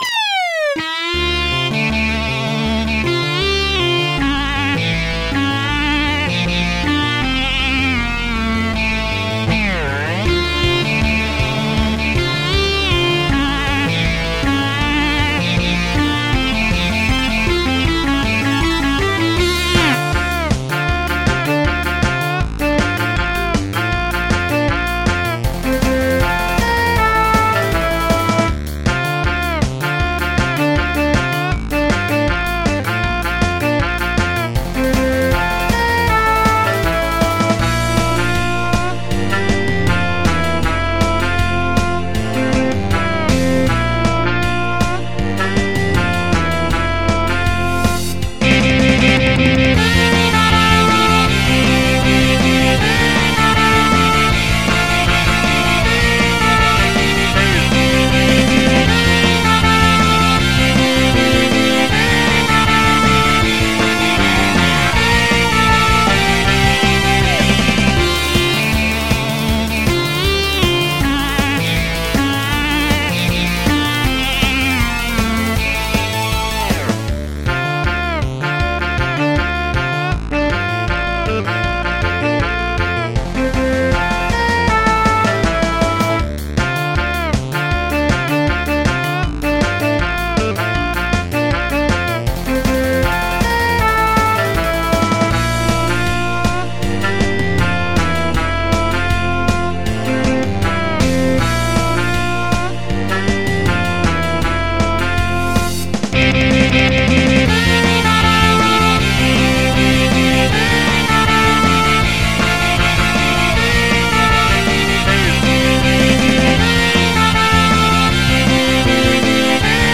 Karaoke Tracks
MP3 (Converted)